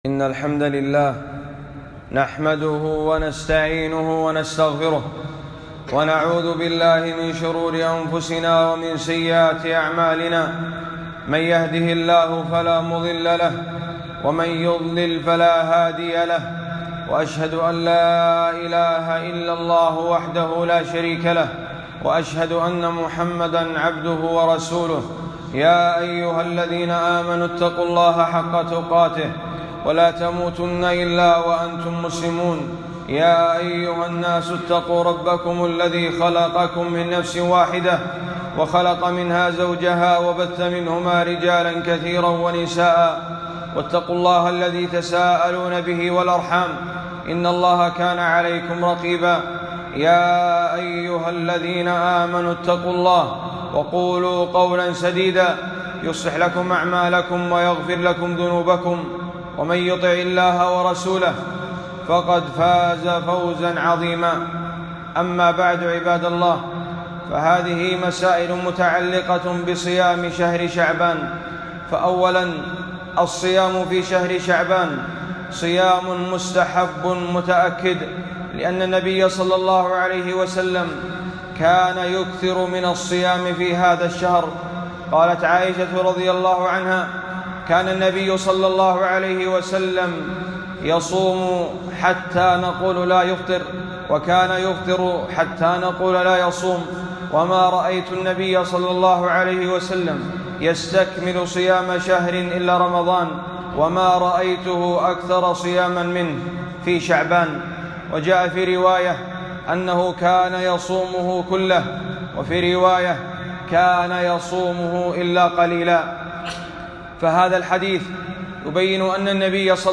خطبة - مسائل في صيام شهر شعبان